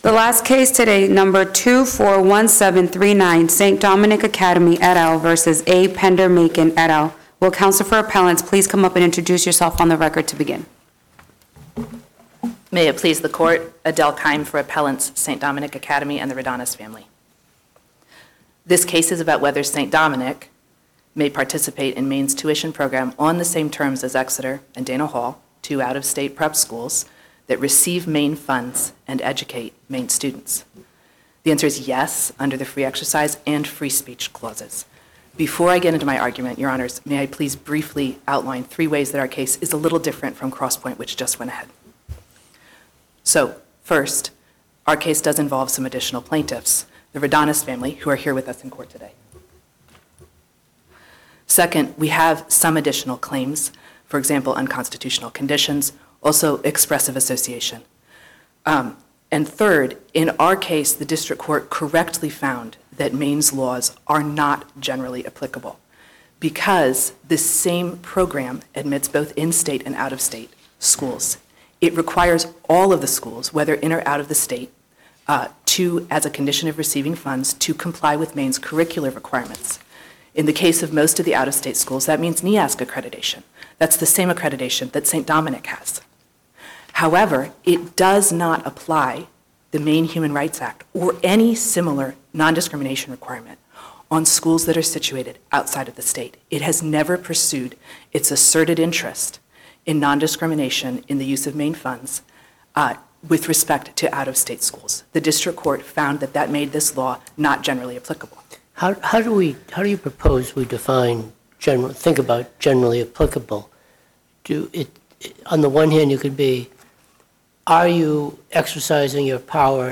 Oral arguments before the First Circuit took place on Tuesday, January 7, 2025, in Boston.